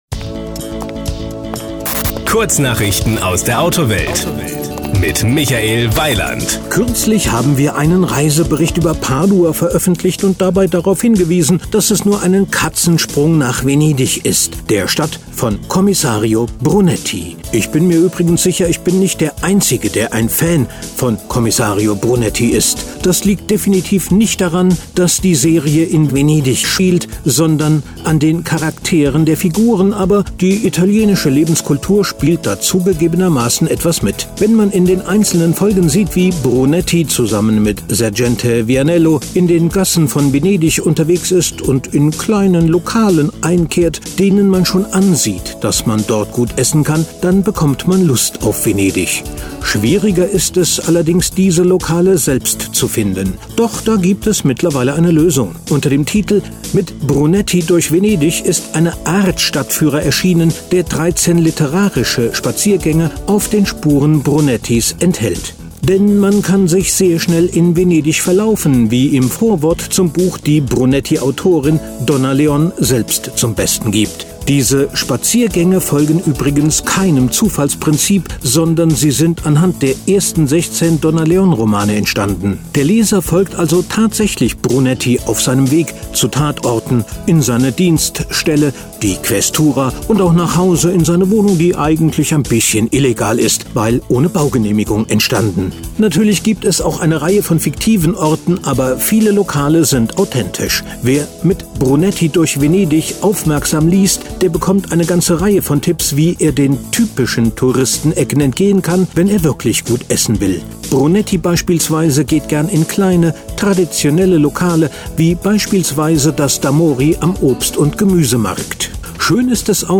Auto-News kann man sich auch vorlesen lassen.